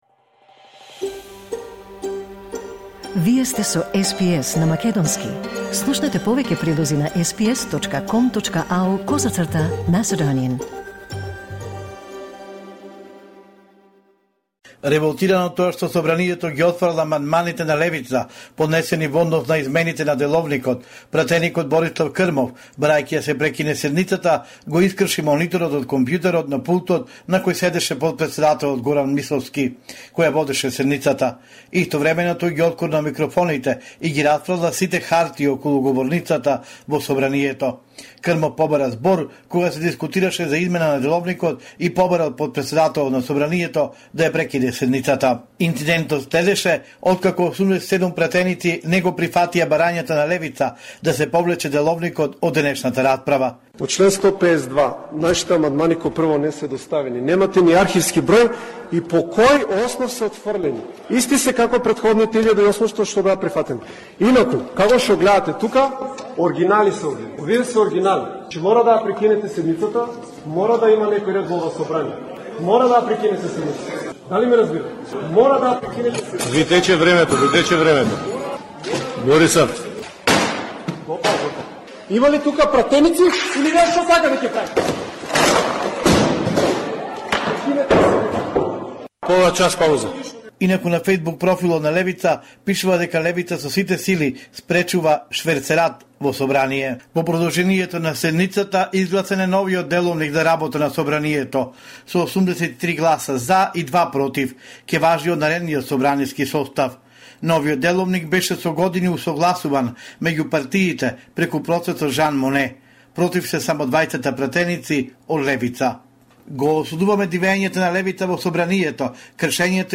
Homeland Report In Macedonian 21 November 2023